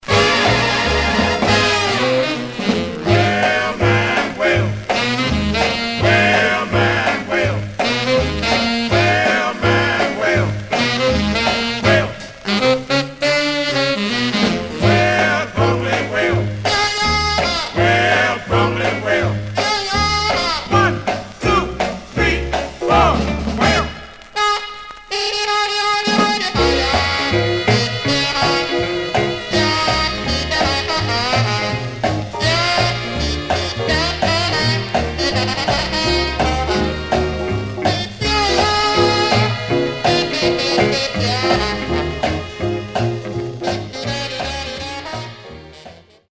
Surface noise/wear Stereo/mono Mono
R&B Instrumental